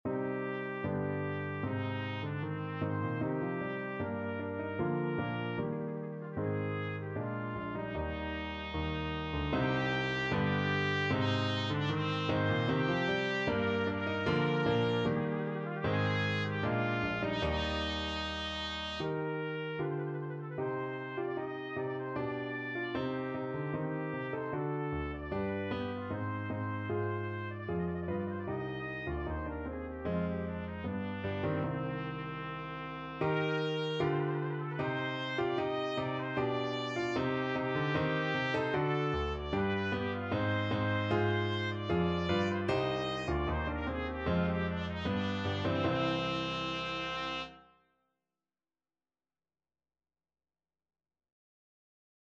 2/2 (View more 2/2 Music)
Steadily =c.76
Classical (View more Classical Trumpet Music)